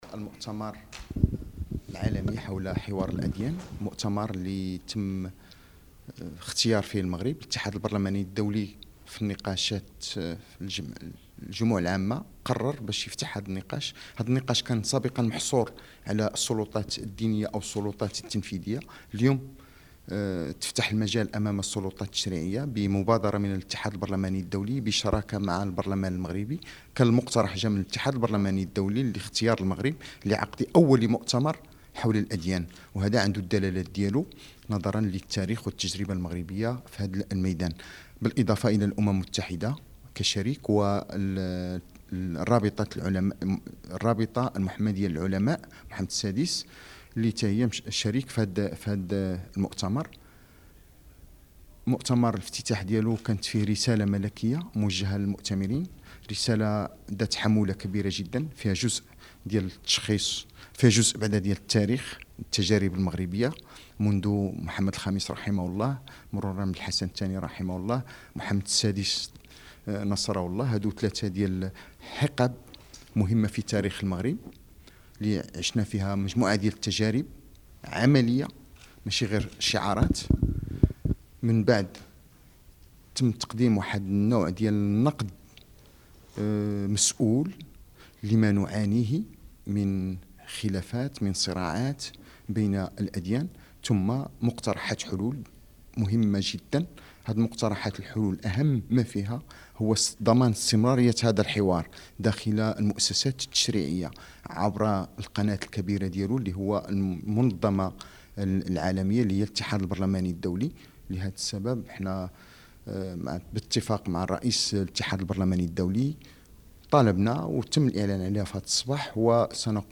تصريح لرئيس مجلس النواب حول مؤتمر حوار الاديان
السيد راشيد الطالبي العلمي بمناسبة أشغال المؤتمر البرلماني حول الحوار بين الأديان، الذي ينظمه، تحت الرعاية السامية لصاحب الجلالة الملك، الاتحاد البرلماني الدولي والبرلمان المغربي بمدينة مراكش من 13 إلى 15 يونيو الجاري.
interview-president2-.mp3